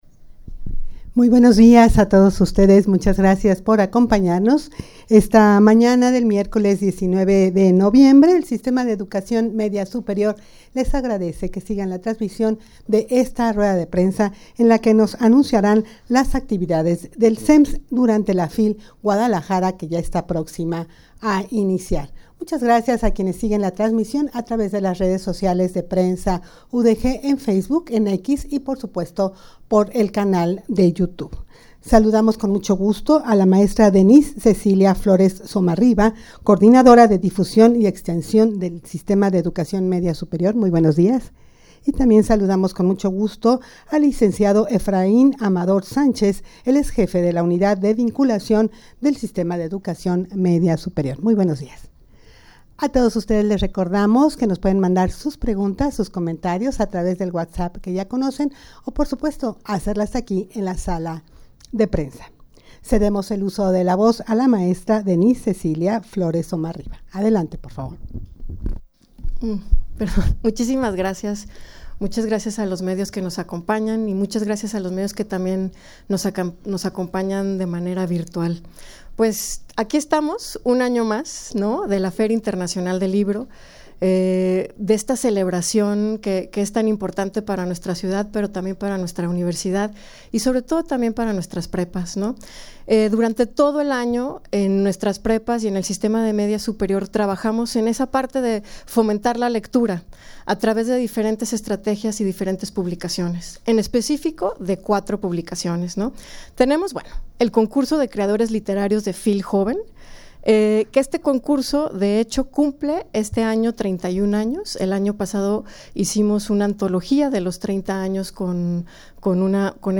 Audio de la Rueda de Prensa
rueda-de-prensa-para-anunciar-las-actividades-del-sems-en-la-fil-guadalajara.mp3